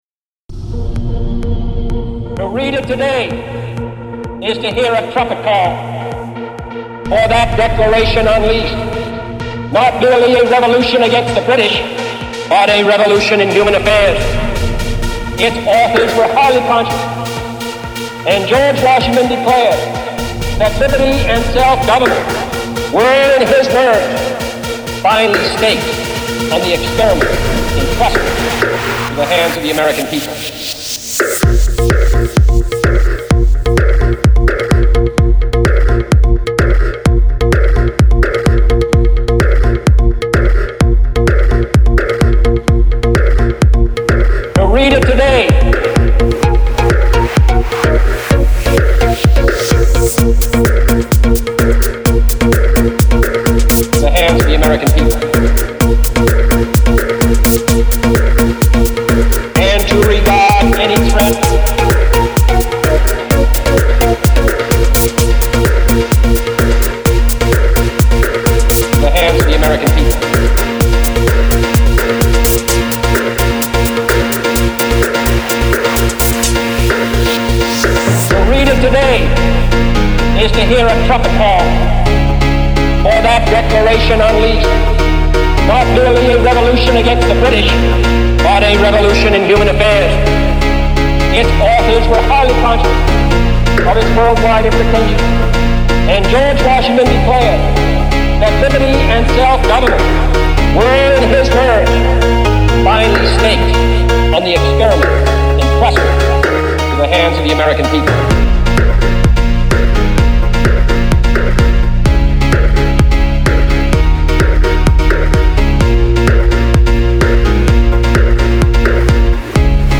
Genre : House